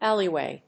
音節álley・wày 発音記号・読み方
/ˈæliˌwe(米国英語), ˈæli:ˌweɪ(英国英語)/
フリガナアリーウェイ